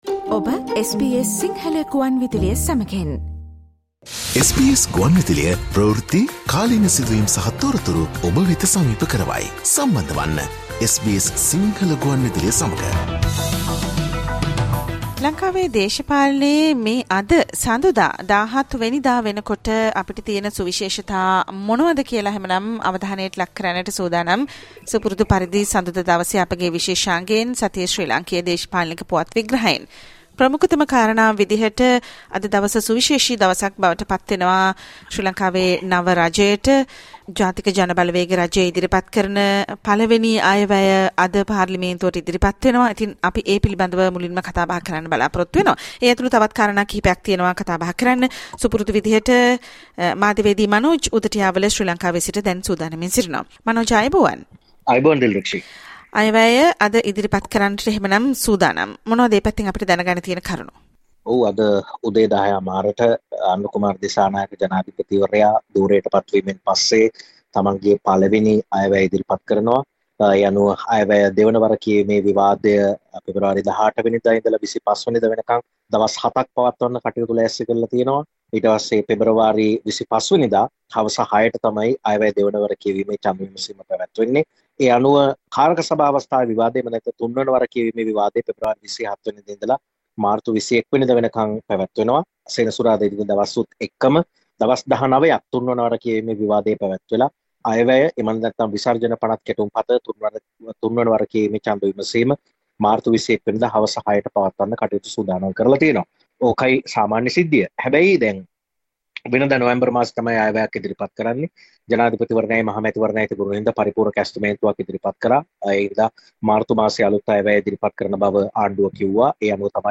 Please note that the two segments broadcasted during the live program have been combined and are now available on the website as a single program.